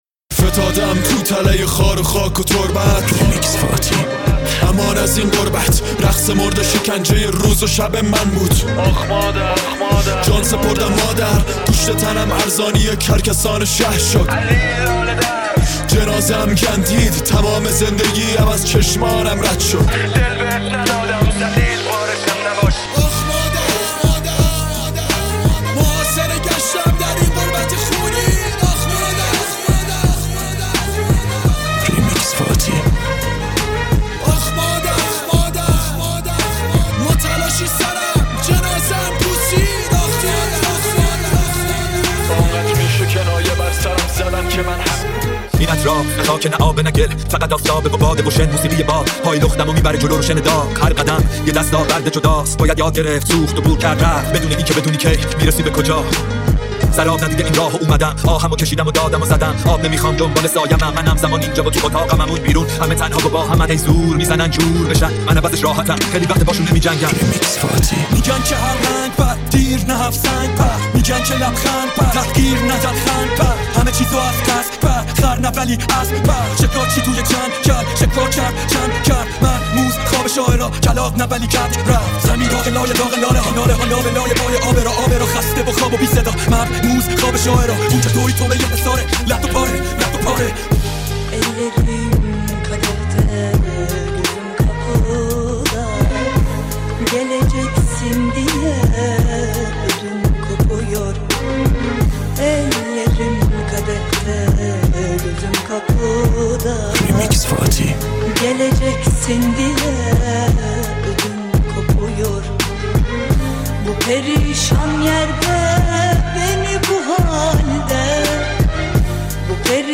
ریمیکس رپ فارسی